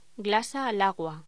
Locución: Glasa al agua
voz